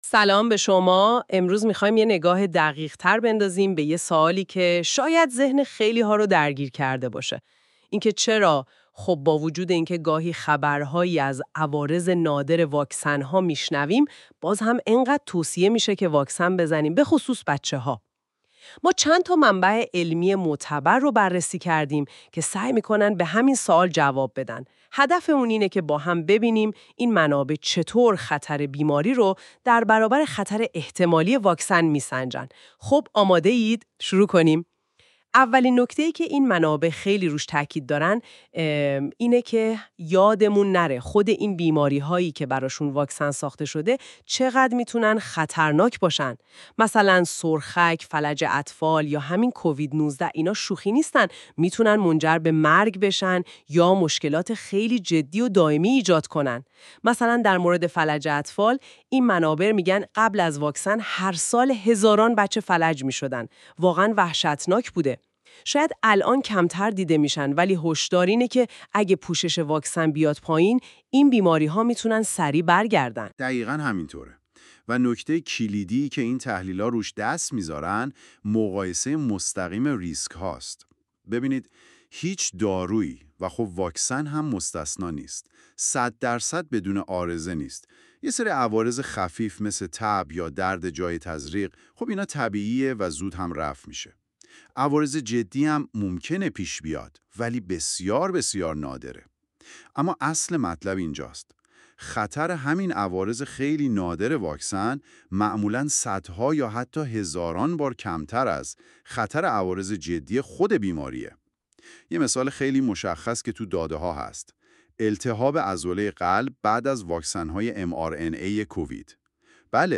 از سری گفتگوهای خانم نوا و آقای حامی درباره حقایق ادعاهای سلامت- قسمت هفتم
«خانم نوا» و «آقای حامی»، دو شخصیت هوش مصنوعی «فکت‌نامه سلامت»، در این قسمت به این موضوع مهم پاسخ می‌دهند.